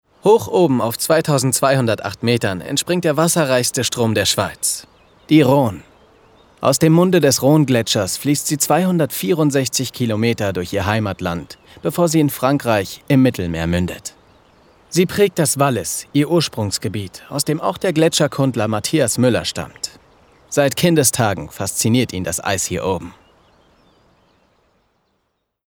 sehr variabel, hell, fein, zart, markant
Jung (18-30)
Commercial (Werbung)